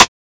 Lunch77 Rim 1.wav